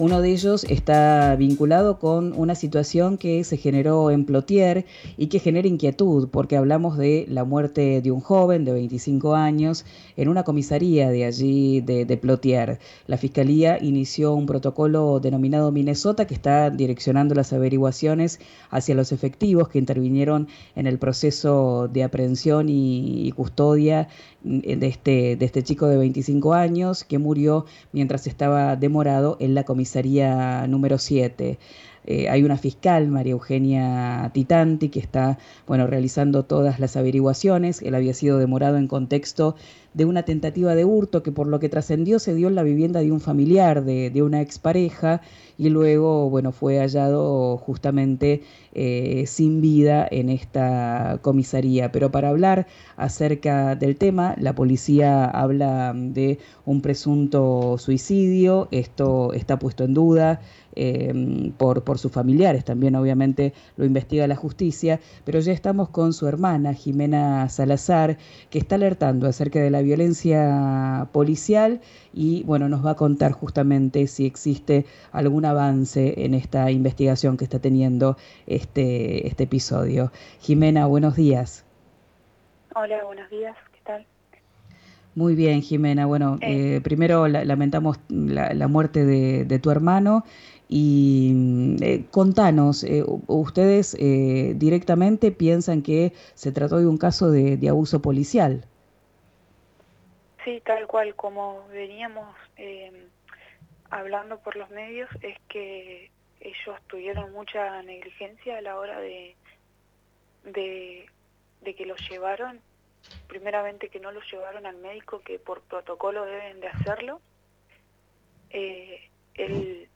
habló con «Arranquemos» por RÍO NEGRO RADIO.